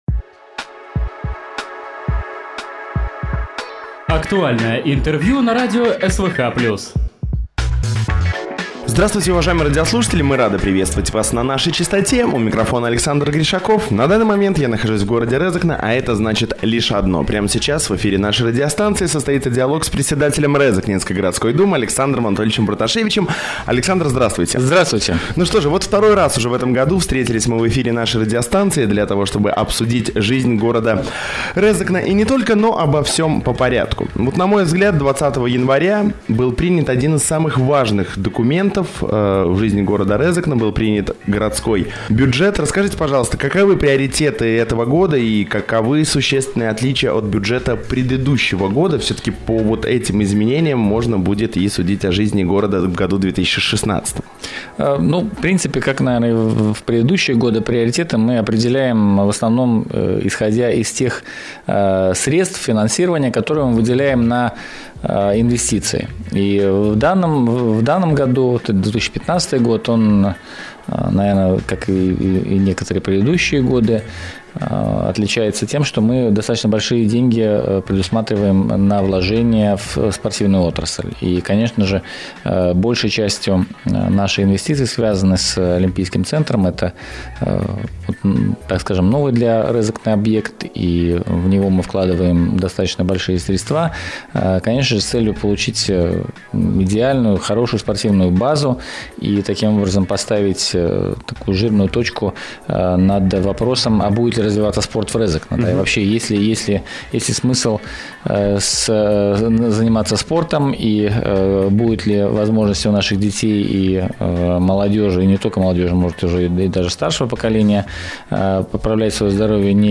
Гость в студии (27.01.16.)
Актуальная информация о происходящем в Резекне из уст председателя самоуправления